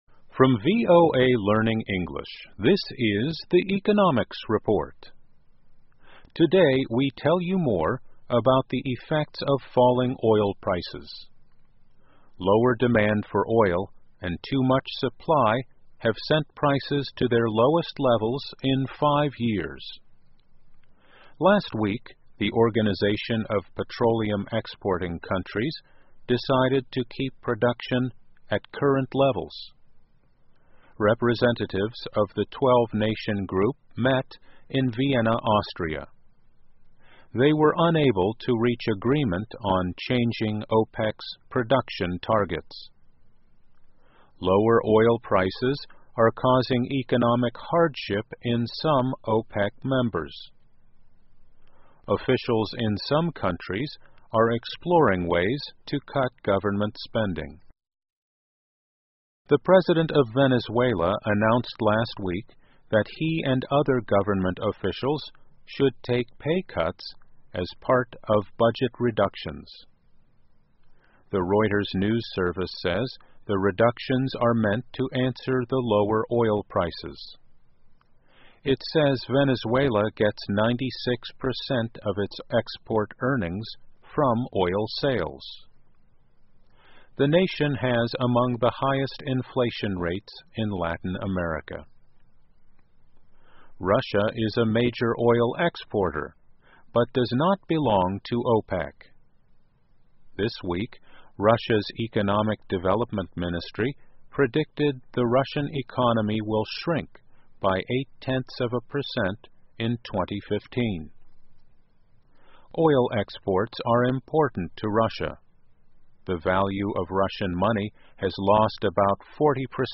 VOA慢速英语2014 Some Asian Countries Gain from Low Oil Prices 听力文件下载—在线英语听力室